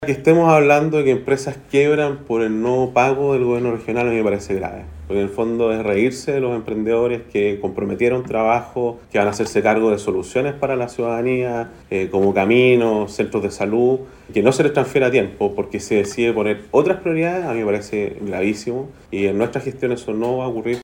La autoridad enfatizó que lo más grave es la deuda de más de 15 mil millones de pesos a empresas contratistas, las cuales a raíz de los servicios prestados y que no han sido cancelados, están al borde de la quiebra.